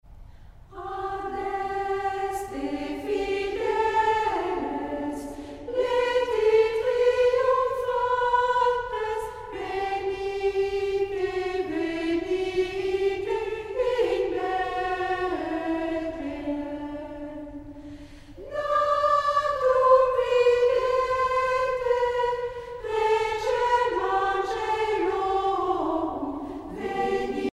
Saint-Serge (chorale)
Pièce musicale éditée